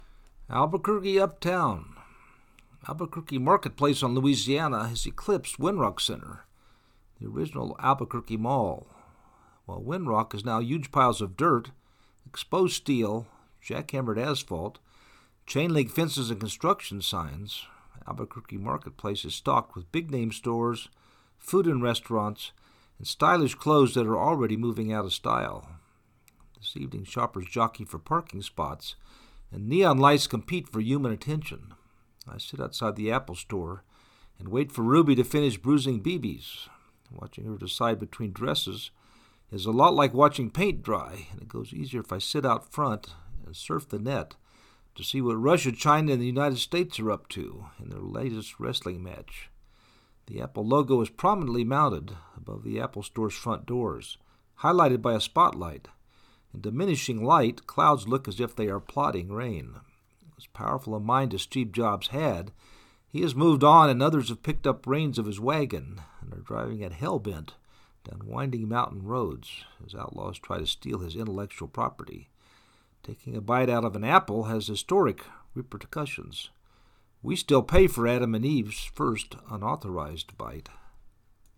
Albuquerque Uptown Apple at night
albuquerque-uptown-4.mp3